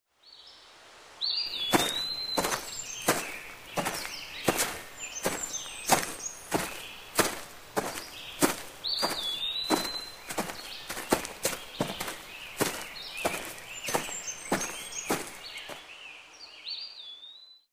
Звуки шагов в лесу
• Качество: высокое
Скачивайте реалистичные записи шагов по мягкой траве, хрустящему осеннему ковру из листьев и утоптанным земляным тропам.